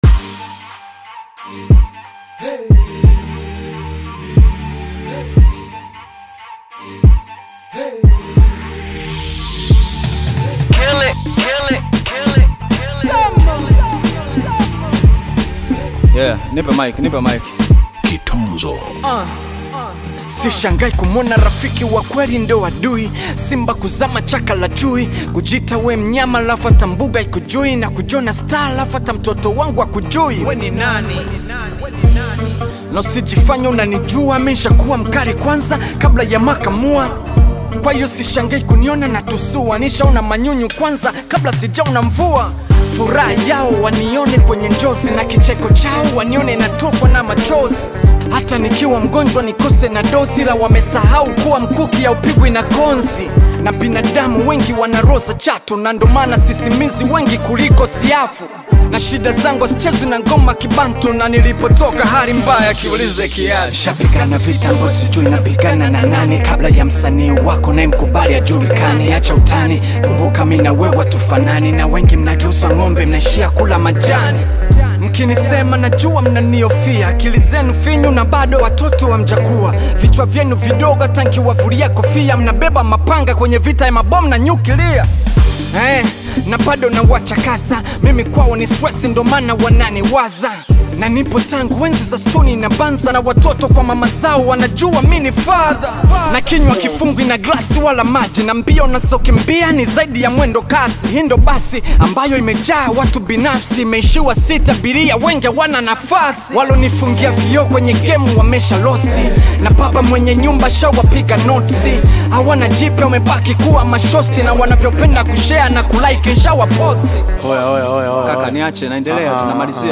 Bongo Flava music track
Tanzanian Bongo Flava artist and rapper